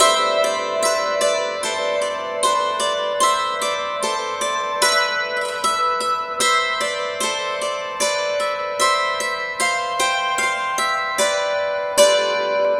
Dulcimer08_75_G.wav